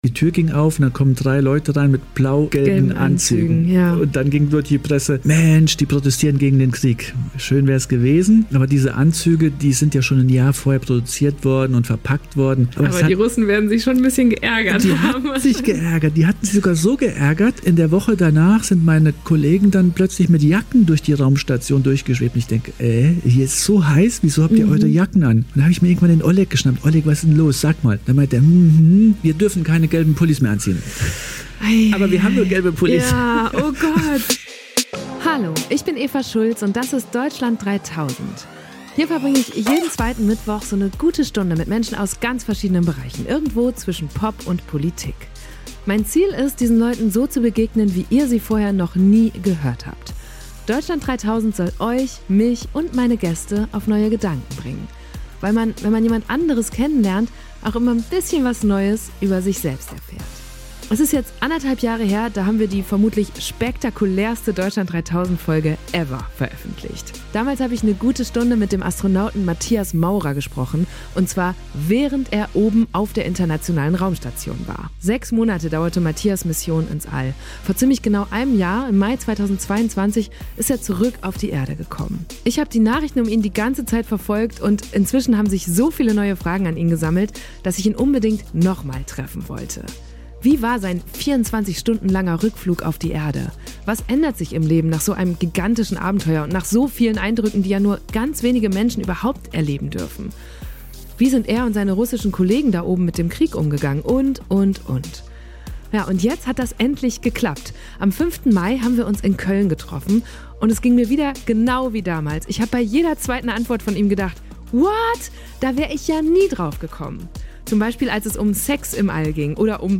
Am 5. Mai haben wir uns in Köln getroffen und es ging mir wieder, genau wie damals.